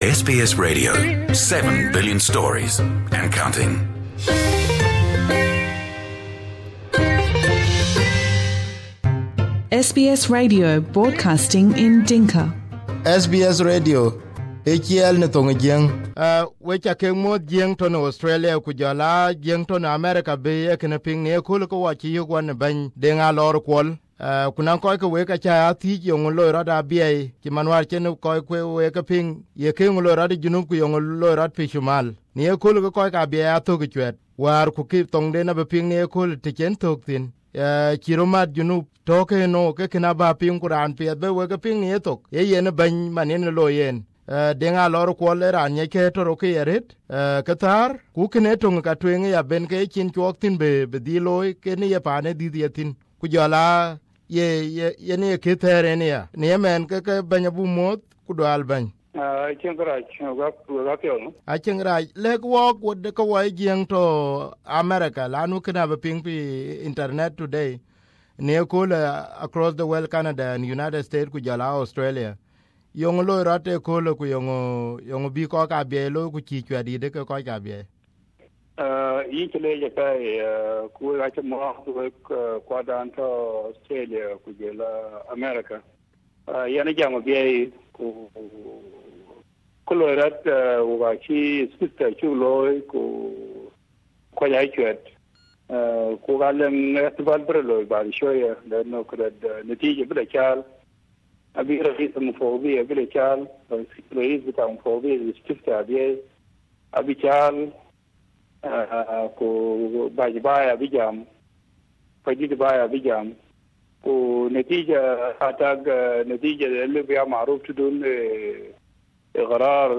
Just a few hours before the results of the Abyei referendum were announced, Deng Alor Kuol a former Minister in the Government of South Sudan and native of Abyei talked to SBS Dinka while in Abyei. Abyei is an area that belongs to the 9 chieftains of Dinka Ngok and a disputed land between South Sudan and North Sudan.